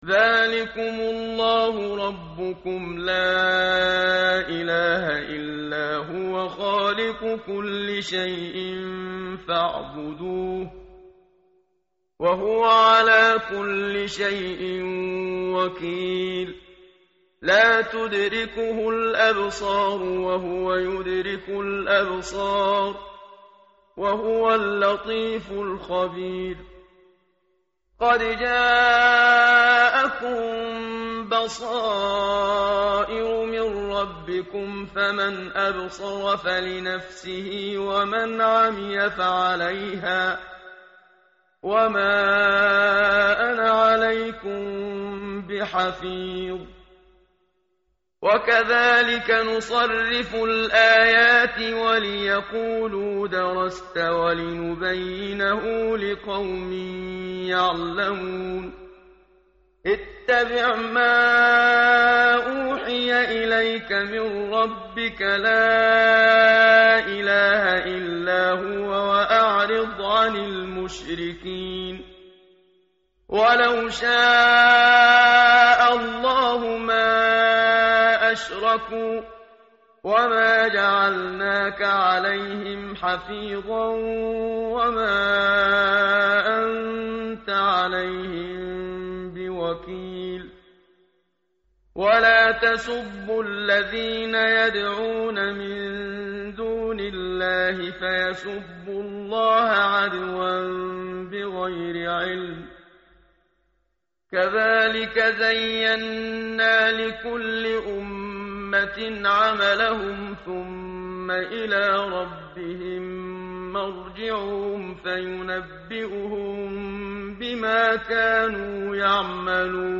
tartil_menshavi_page_141.mp3